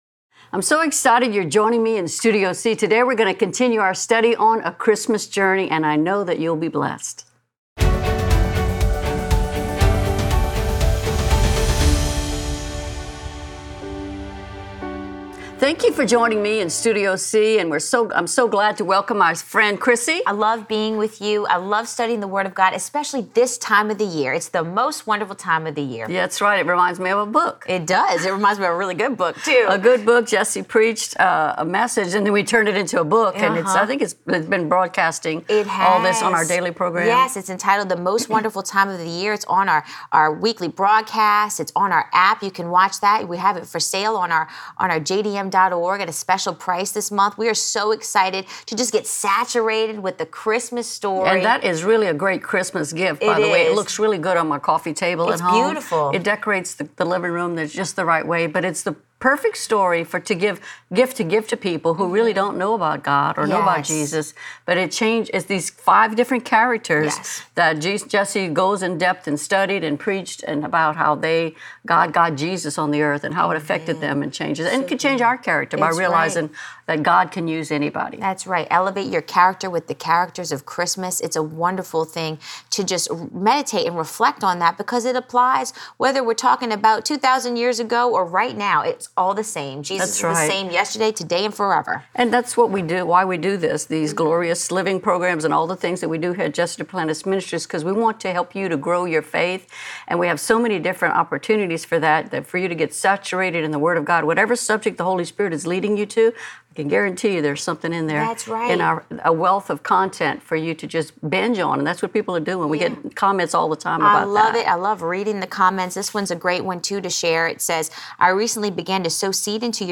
in Studio C